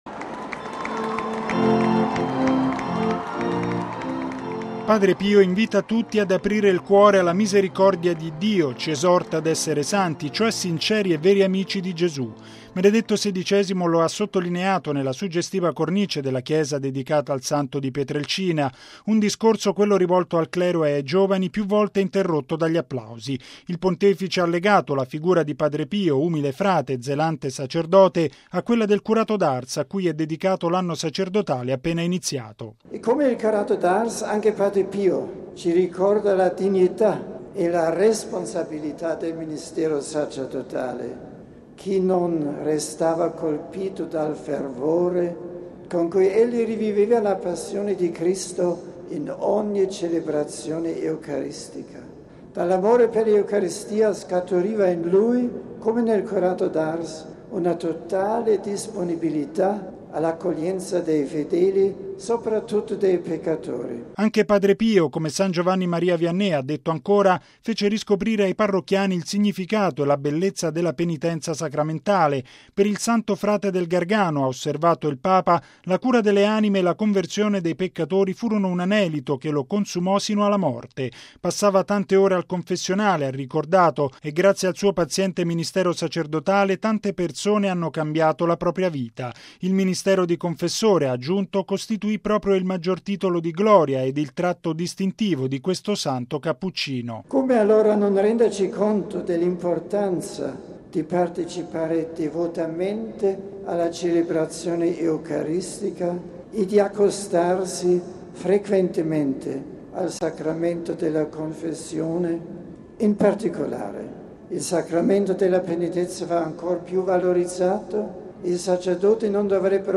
Applausi
Un discorso, quello rivolto al clero e ai giovani, più volte interrotto dagli applausi.